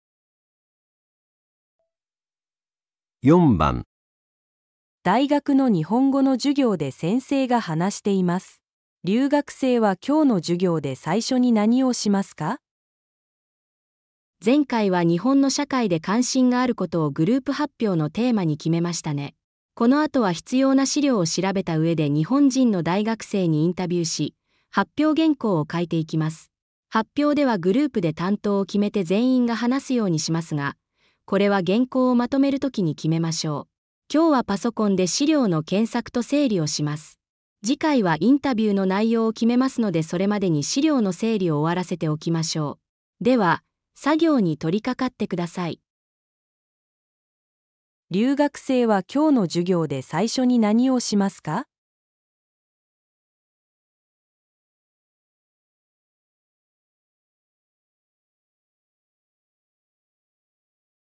大学的日语课上，老师正在讲话。